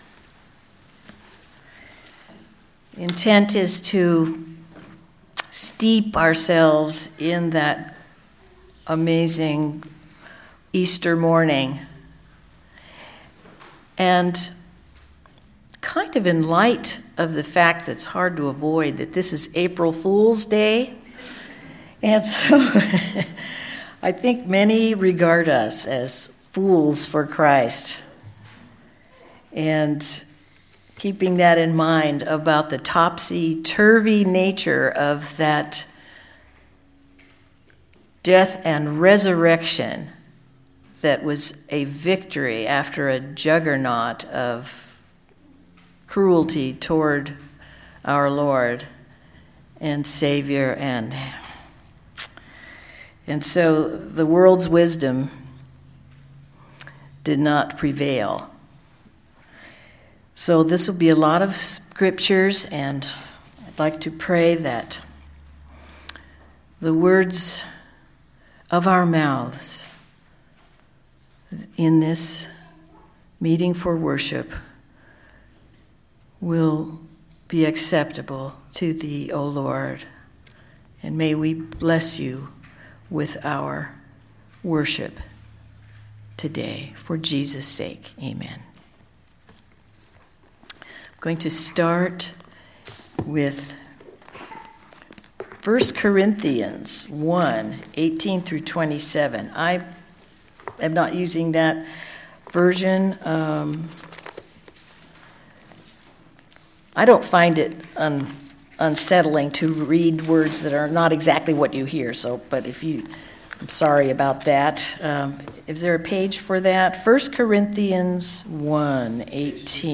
Message for April 1, 2018
Listen to the most recent message from Sunday worship at Berkeley Friends Church.